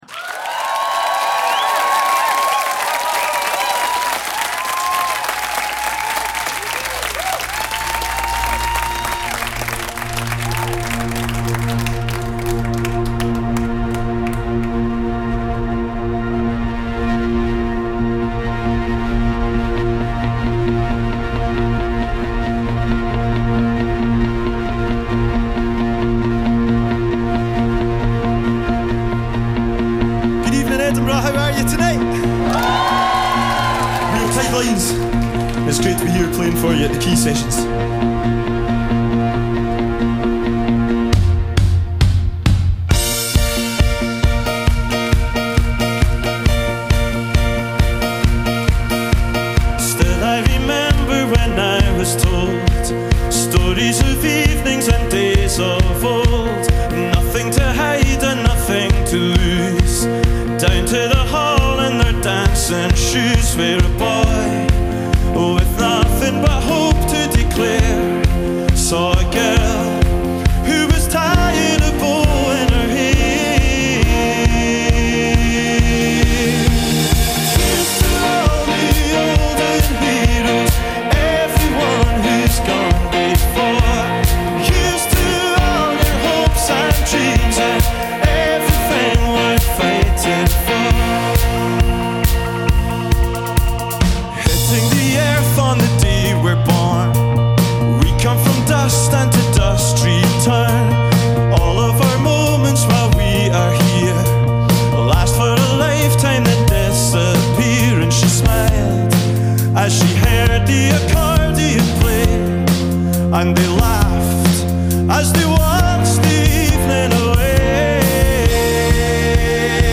in concert at the 2024 Edinburgh Festival
New Folk-Indie